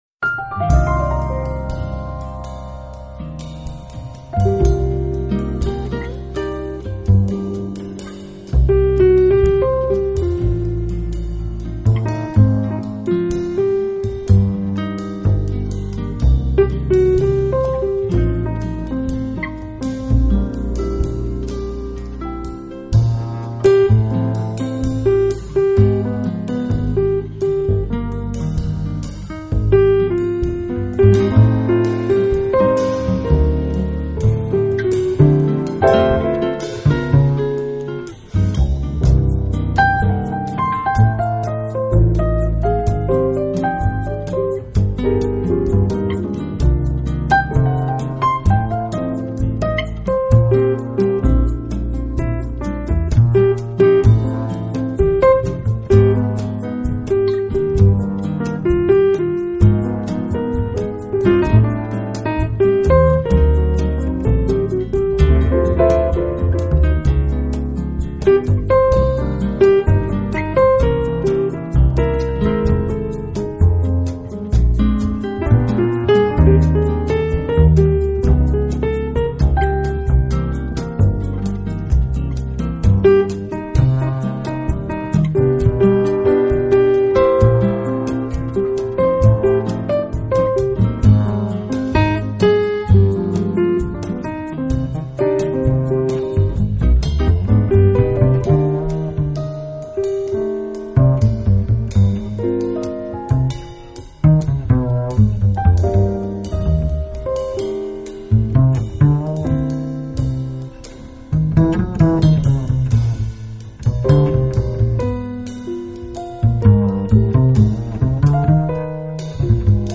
【爵士钢琴】
Genre: Jazz, Piano Jazz, Smooth Jazz
Quality: MP3 / Joint Stereo